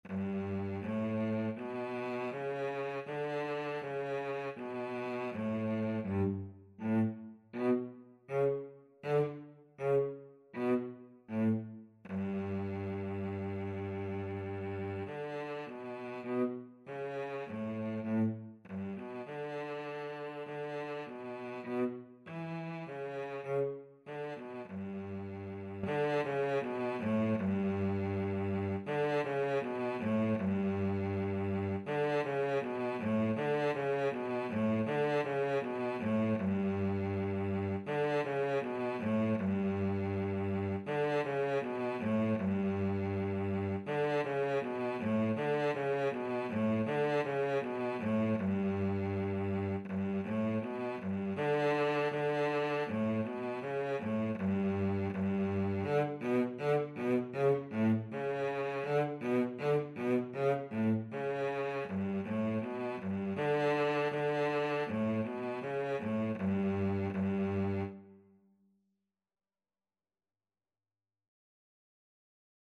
4/4 (View more 4/4 Music)
G3-E4
Beginners Level: Recommended for Beginners
Cello  (View more Beginners Cello Music)
Classical (View more Classical Cello Music)